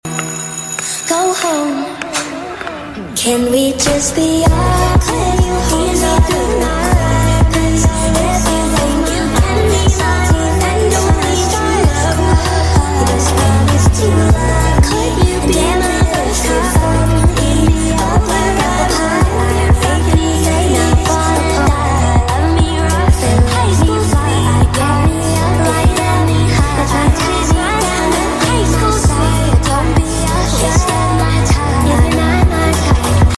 layered audio